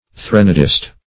Meaning of threnodist. threnodist synonyms, pronunciation, spelling and more from Free Dictionary.
Search Result for " threnodist" : The Collaborative International Dictionary of English v.0.48: Threnodist \Thren"o*dist\, n. One who composes, delivers, or utters, a threnode, or threnody.